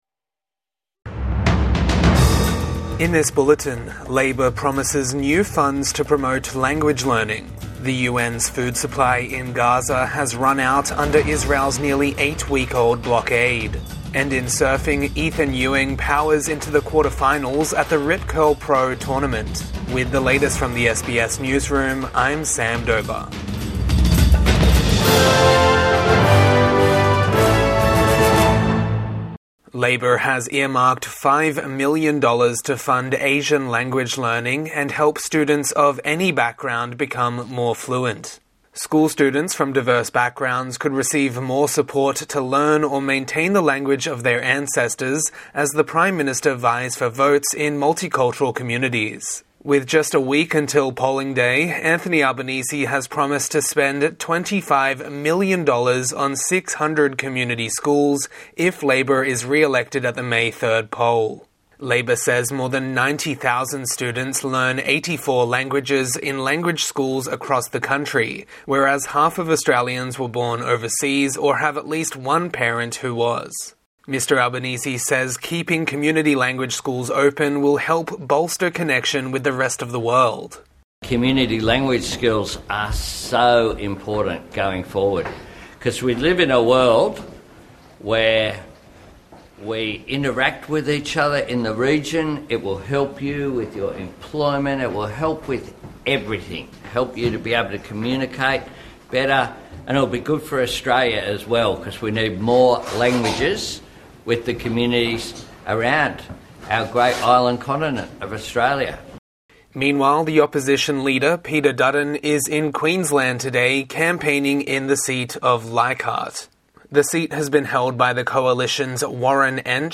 Anthony Albanese pledges new funds for language learning | Midday News Bulletin 26 April 2025 | SBS News